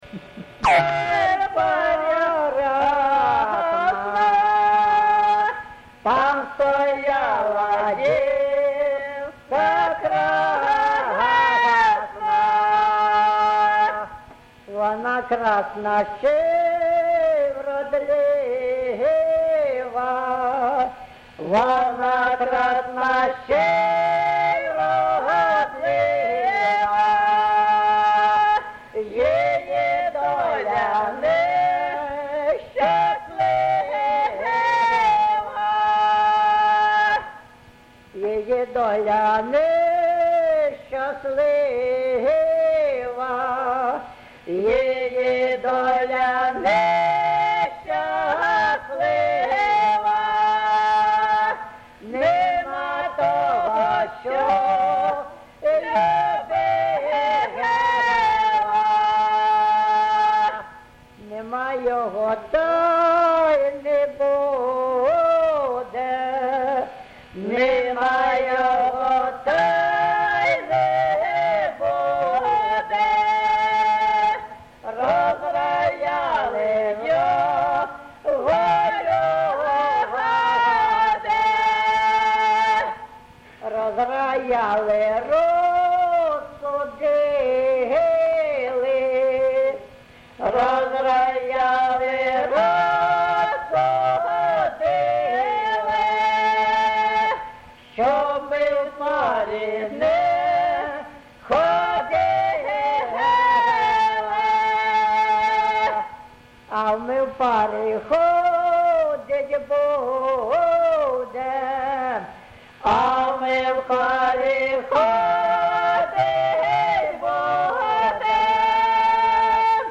ЖанрПісні з особистого та родинного життя
Місце записум. Антрацит, Ровеньківський район, Луганська обл., Україна, Слобожанщина